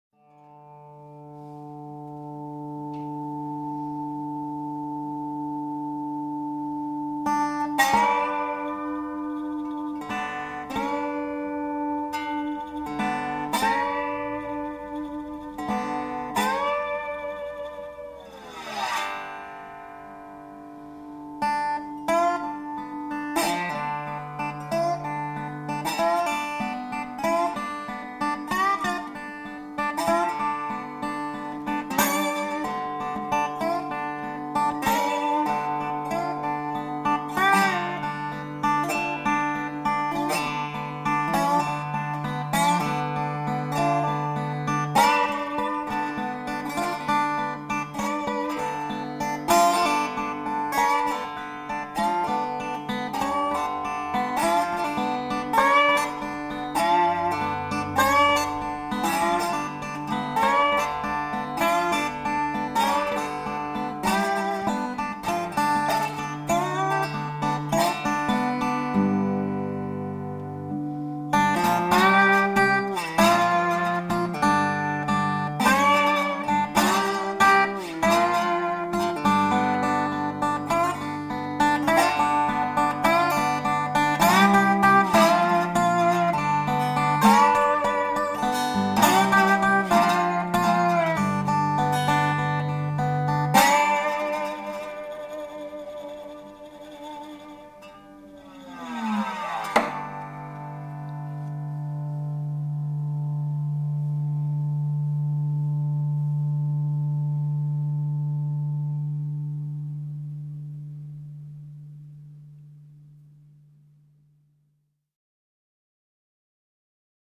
guitars
delta bluesman influence
Acoustic bottleneck slide